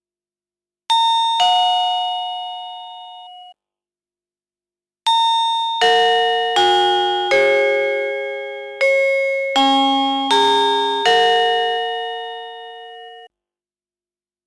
[d] Gong Sunnybell weiss / drahtlos / Reichweite: 80m / 2 Melodien / Batterie Gong: exklusive (3xLR14), Batterie Sender: inklusive (1xLR23A 12V) / Masse:...
Melodien_102988.mp3